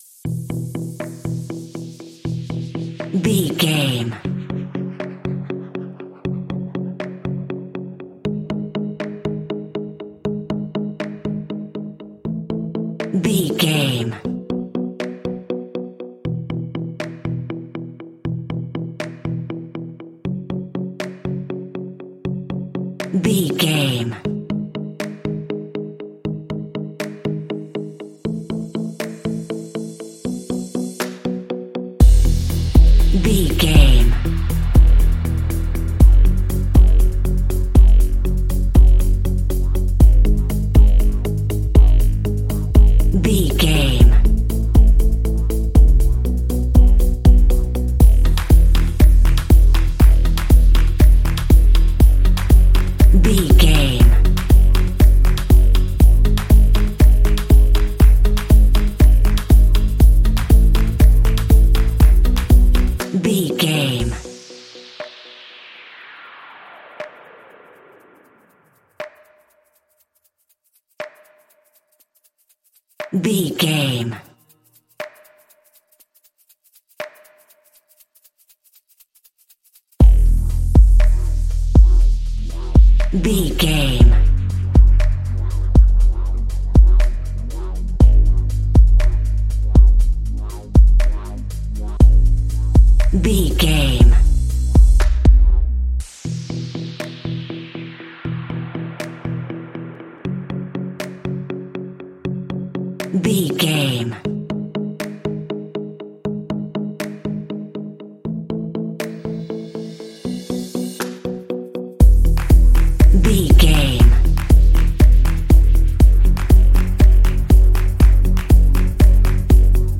Uplifting
Ionian/Major
Fast
upbeat
ambient
electronic
corporate
piano
synth
pop
bright
energetic
drum machine